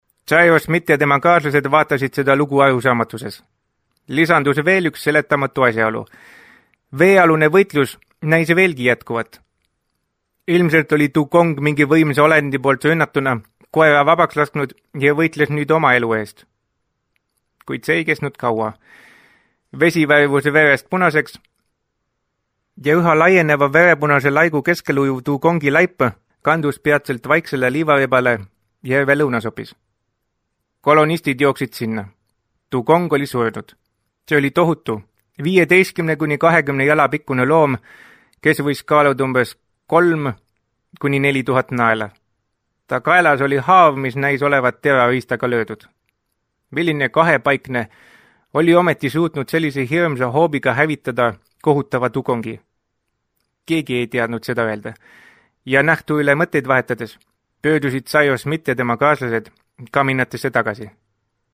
Estonian speaker, voice over, audio book narrator
Sprechprobe: Industrie (Muttersprache):